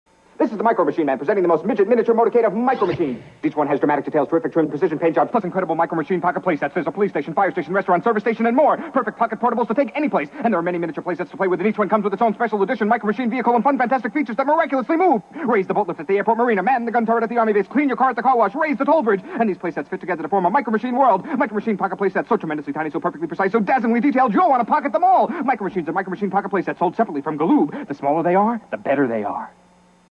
1987 Micro Machines Car Playset Commercial (Featuring John Moschitta the Micro Machine Man).mp3